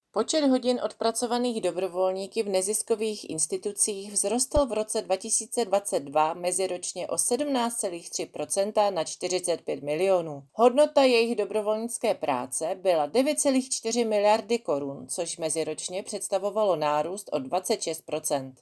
Vyjádření